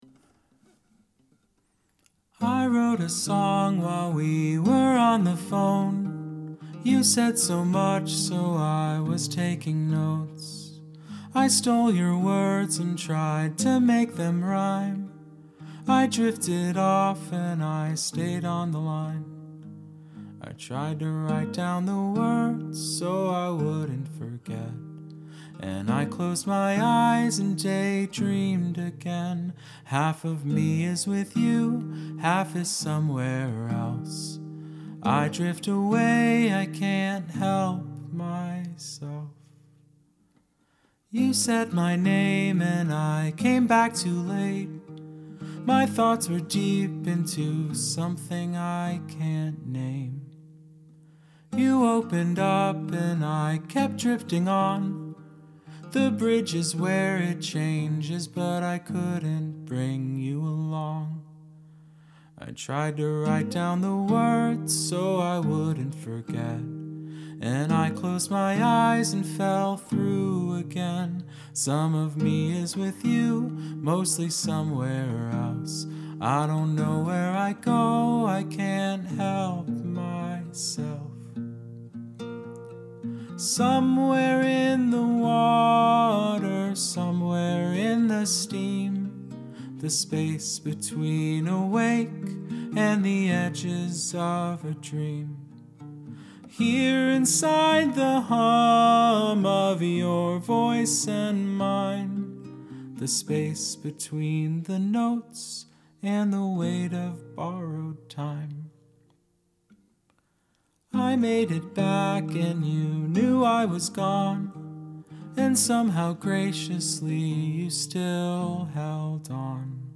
I'm so here for this, it feels like a midwest emo lullaby.
excellent vocals and presentation
Such a clear and effective approach, great voice and melody.